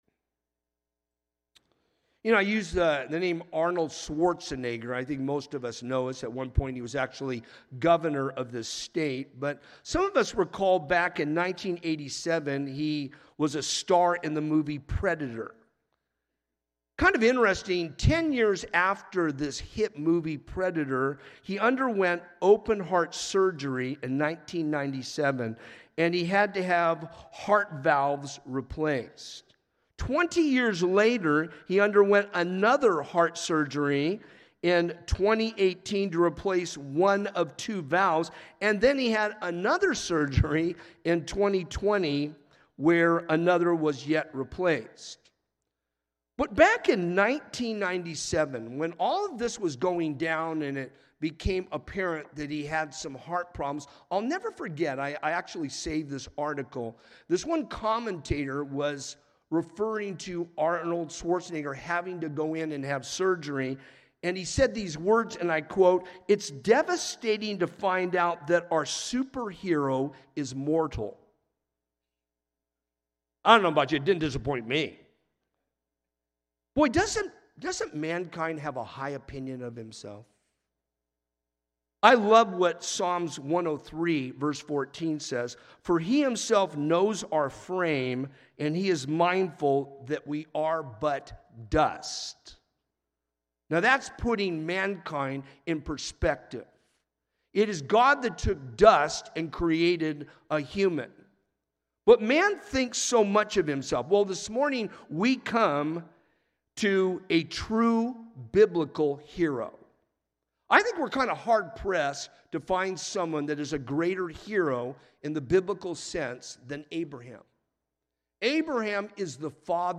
Message: “Finishing Well”
From Series: "Sunday Morning - 10:30"